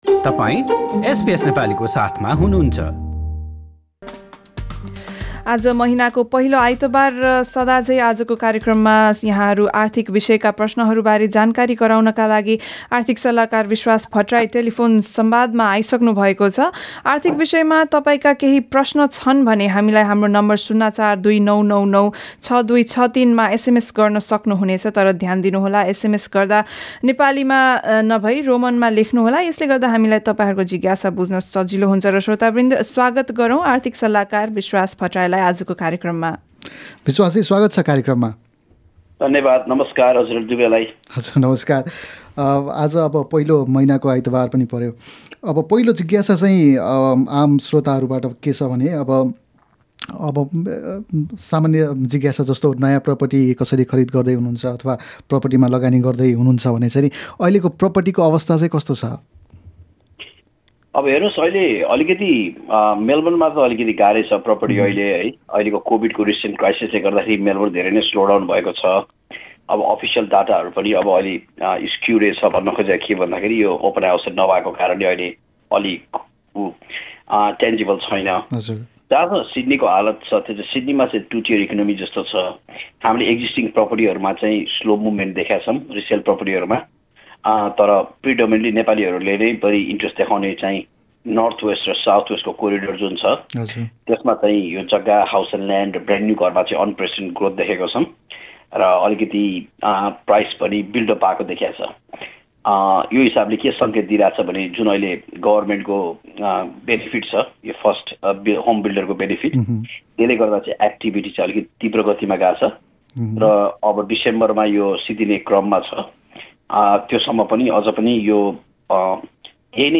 यस मासिक शृङ्खलामा समावेश विषयहरु: घर किनबेच बजारको हालको अबस्था बजेटबाट के अपेक्षा राख्ने साना व्यवसायीलाई यो बजेटमा के छ निजी बिमा कम्पनि रोज्दा के कुरामा ध्यान पुर्‍याउने यी विषय सहितको कुराकानी माथि रहेको मिडिया प्लेयरबाट सुन्नुहोस्।